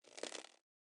Creak 1.wav